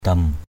/d̪ʌm/ 1. (d.) thanh niên = jeune homme. mbeng katuw daok tagei, khing kamei katuw daok dam O$ kt~| _d<K tg[, A{U km] kt~@ _d<K d’ ăn lúc còn răng, cưới...